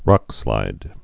(rŏkslīd)